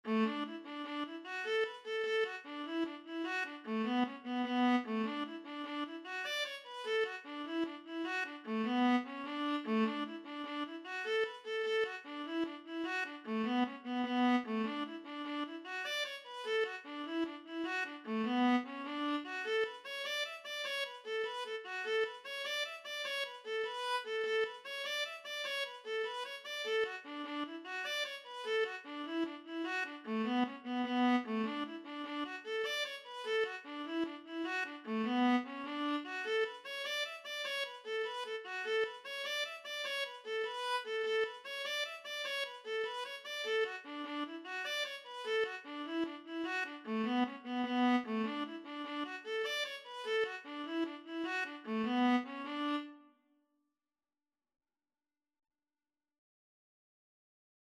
D major (Sounding Pitch) (View more D major Music for Viola )
6/8 (View more 6/8 Music)
A4-E6
Viola  (View more Intermediate Viola Music)
Traditional (View more Traditional Viola Music)
Irish
on298_st_patricks_day_VLA.mp3